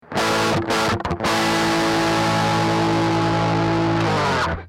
Fichiers son mp3 d'inter-modulation du MB Studio Préamp : (08/12/2004)
En rythmique Bridge
MB-SP_sat-bridge_mod.mp3